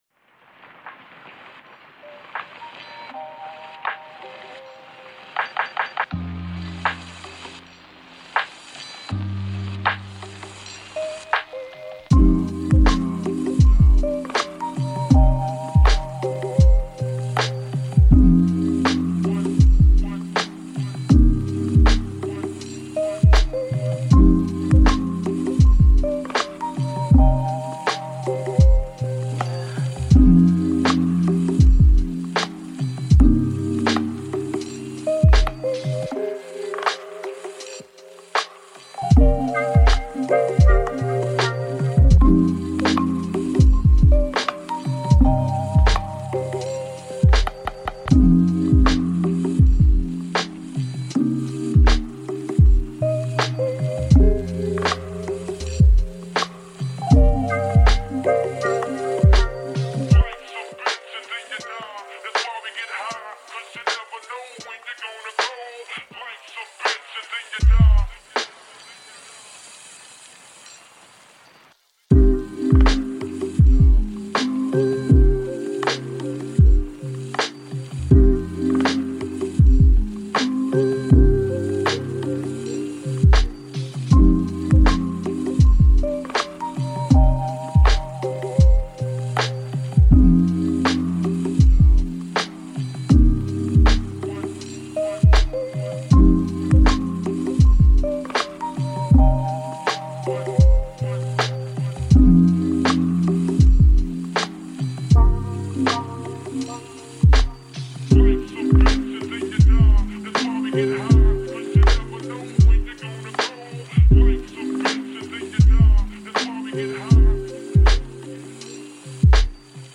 Café Pluie : Sons Pour Focus